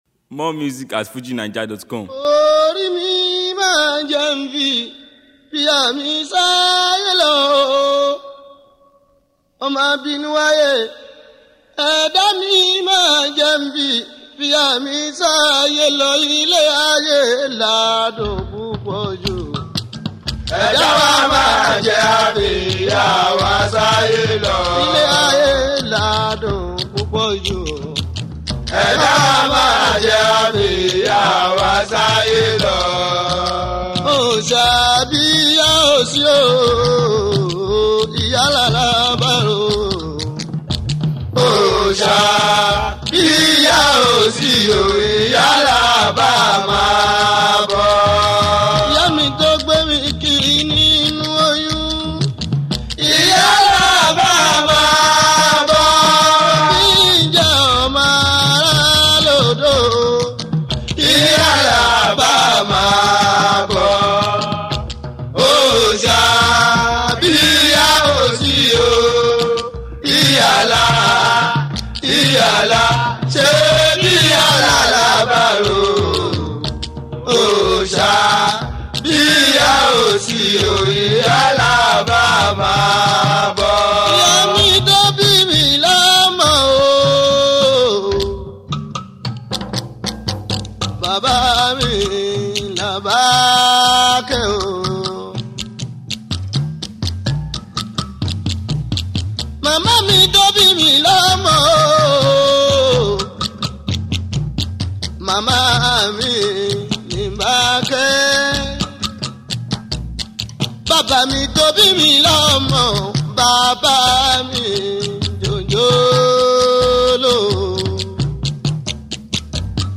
Fuji